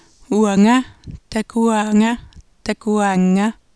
7.7: grønlandsk [uæŋæ tækuæ:ŋæ tækuæ:ŋ:æ]